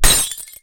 shattering.wav